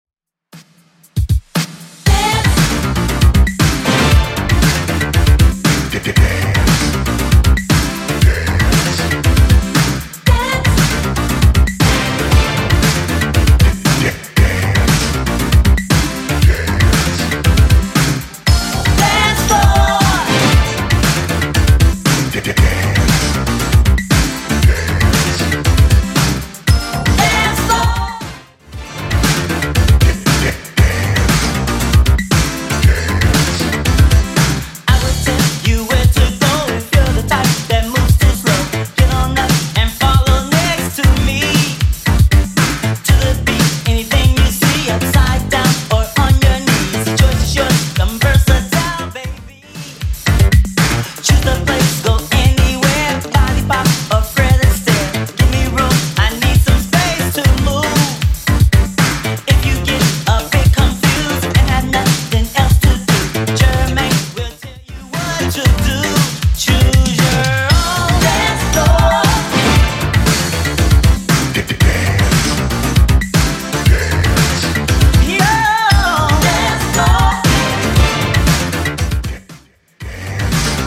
Genre: 80's Version: Clean BPM: 146